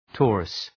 {‘tɔ:rəs}